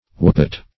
wappet - definition of wappet - synonyms, pronunciation, spelling from Free Dictionary
wappet - definition of wappet - synonyms, pronunciation, spelling from Free Dictionary Search Result for " wappet" : The Collaborative International Dictionary of English v.0.48: Wappet \Wap"pet\, n. A small yelping cur.